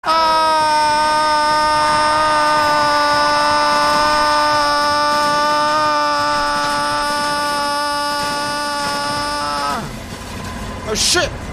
Play, download and share Raven AAAAAAAAAA original sound button!!!!
raven-aaaaaaaaaa.mp3